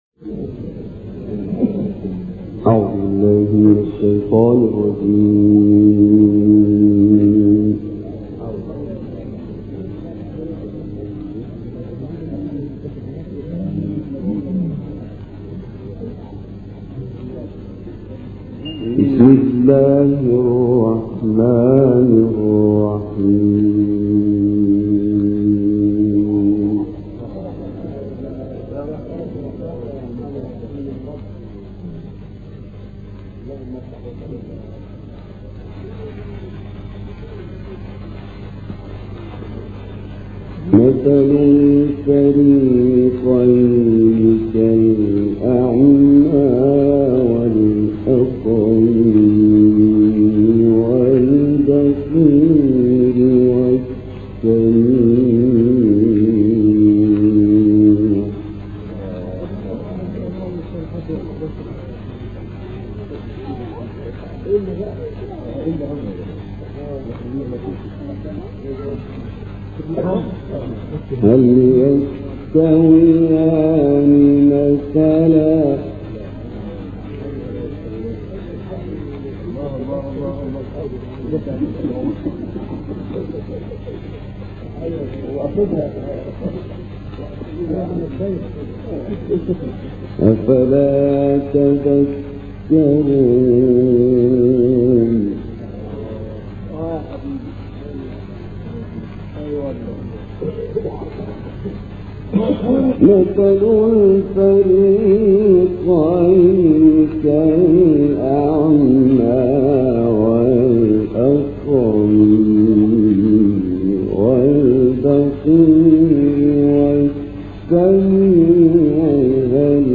تلاوت سوره هود
تلاوت آیاتی از سوره مبارکه هود